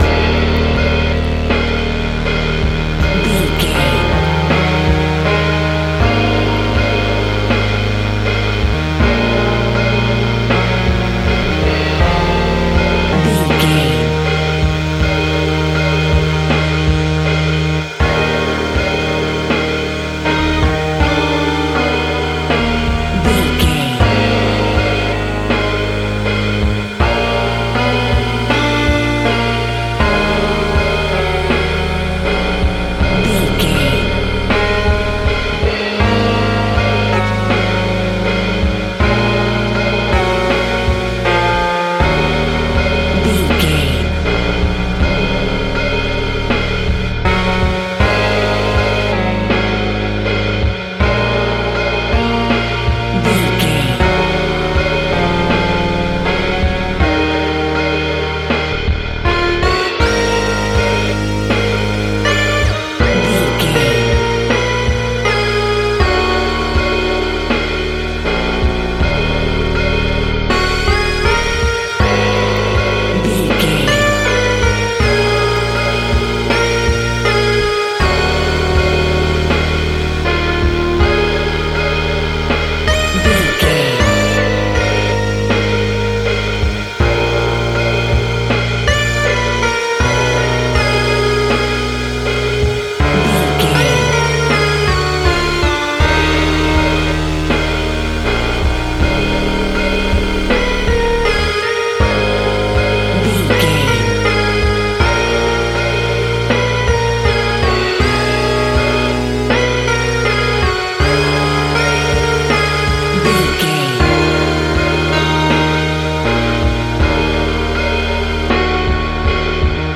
Aeolian/Minor
E♭
scary
ominous
haunting
eerie
synthesiser
piano
drums
horror
Synth Pads
atmospheres